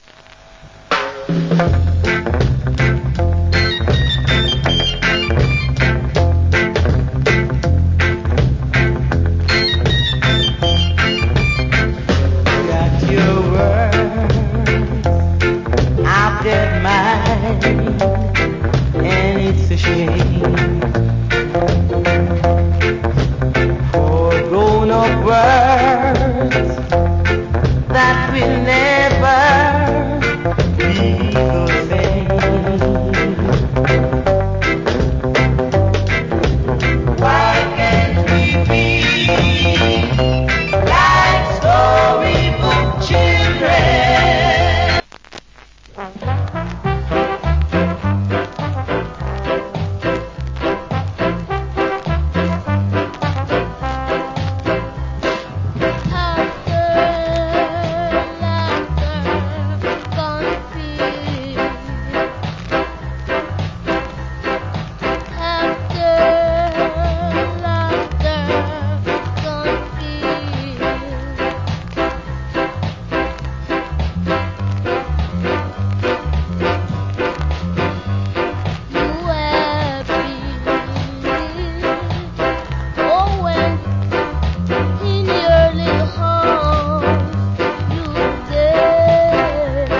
Cool Rock Steady Vocal.